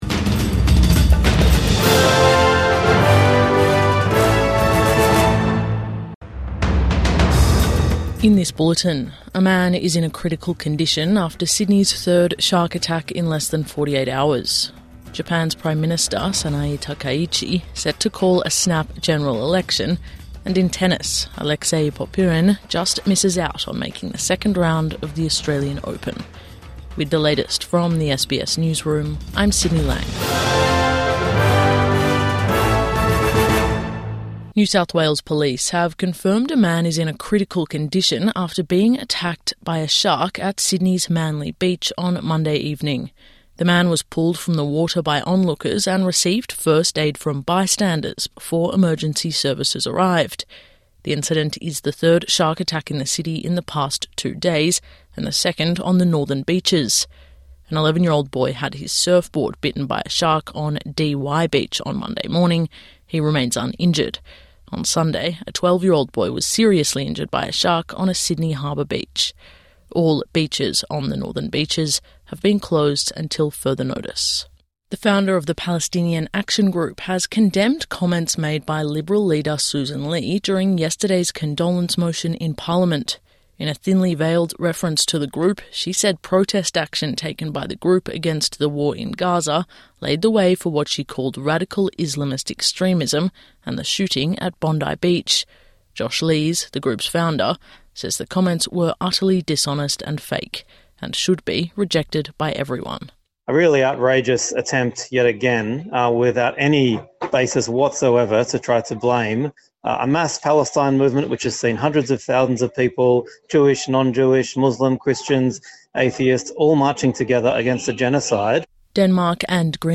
Two critical after three shark attacks in Sydney over two days | Morning News Bulletin 20 January 2026